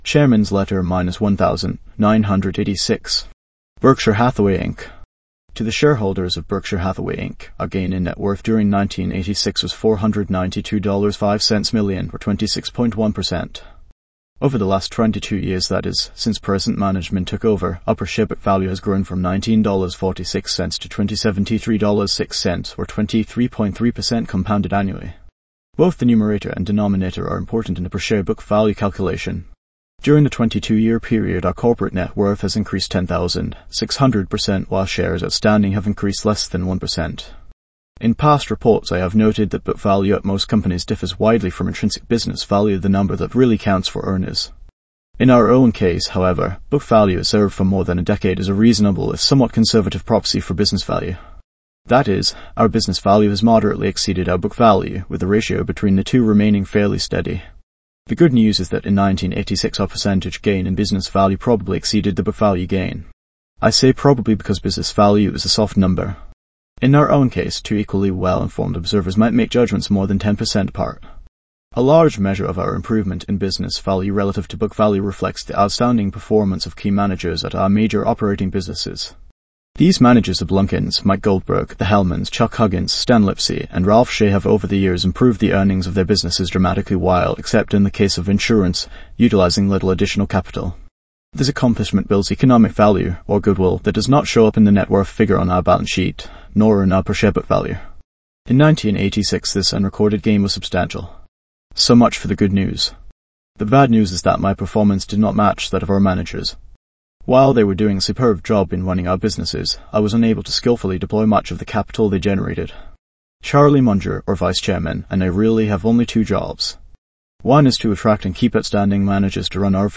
value-investors-tts